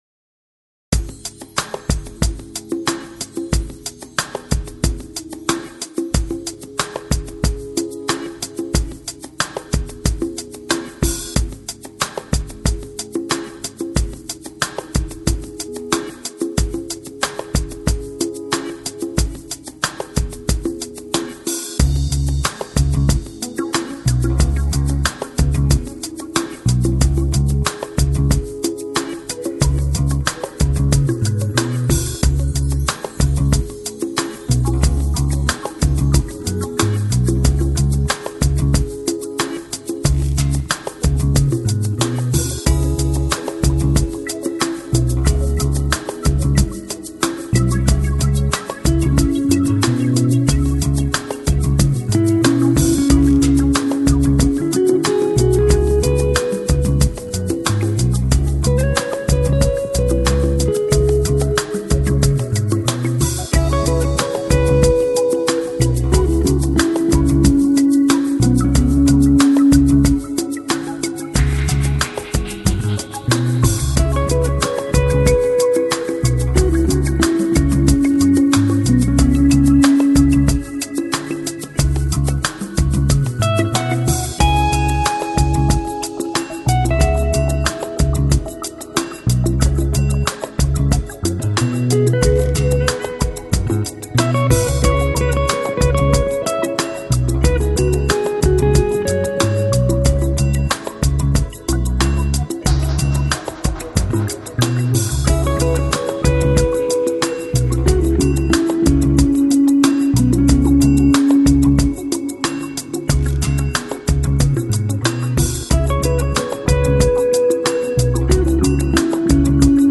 Balearic, Downtempo Издание